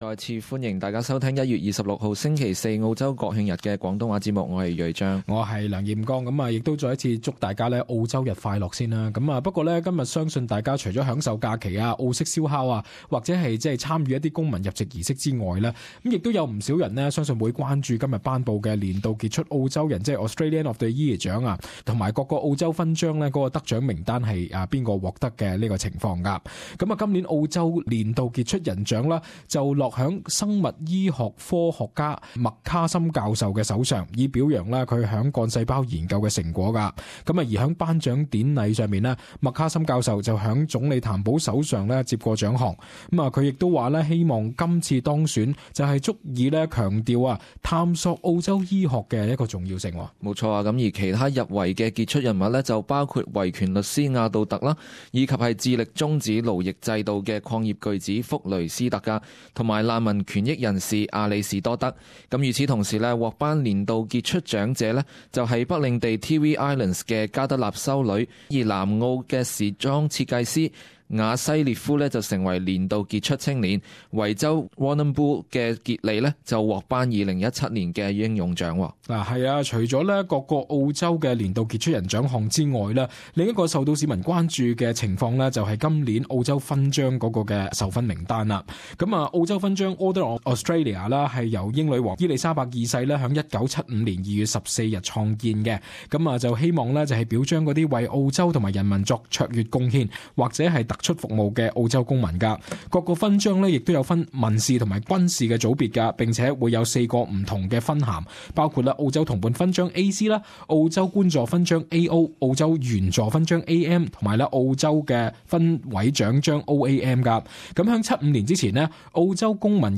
【時事報導】年度傑出澳洲人及澳洲勳章